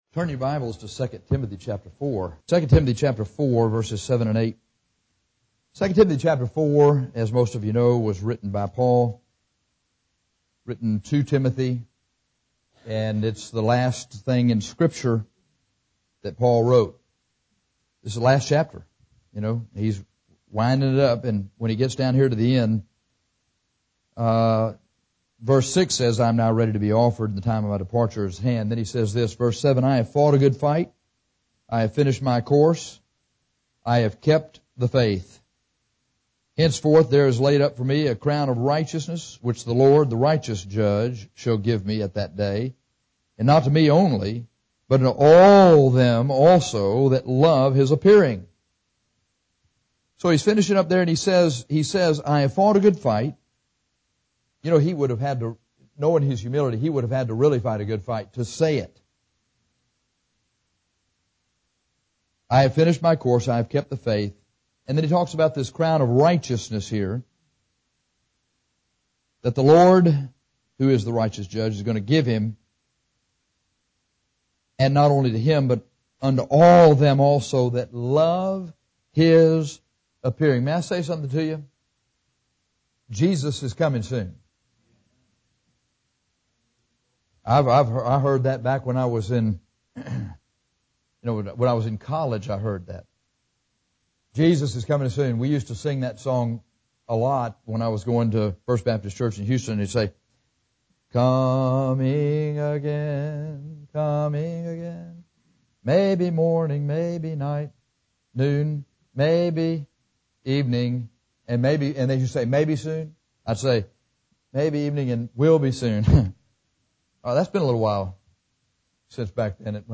This sermon will help you get ready for the soon arrival of Jesus Christ